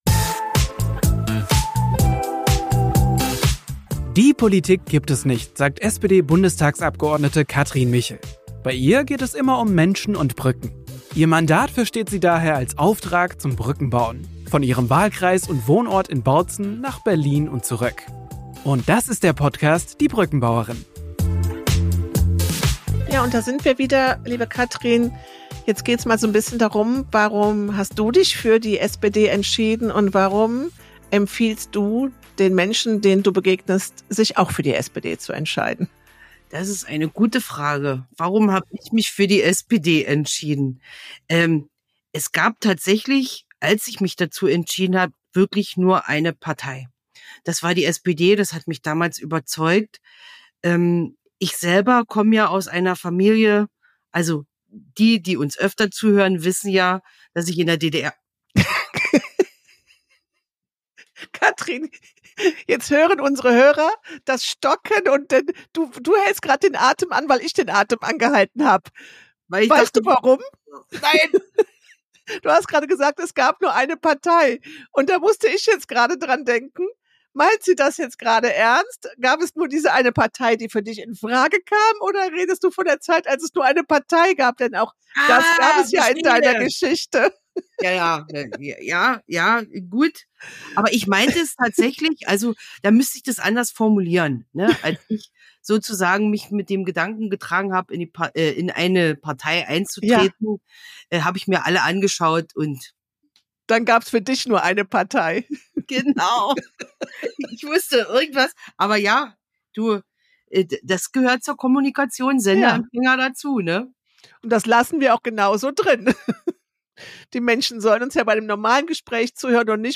Natürlich geht es in dem Polittalk um die historische Bedeutung der Sozialdemokratischen Partei, aber auch darum, warum die Werte, für die die SPD steht, aktueller denn je sind: ihr Einsatz für soziale Gerechtigkeit, gute Arbeitsbedingungen, bezahlbaren Klimaschutz, gleiche Bildung für alle.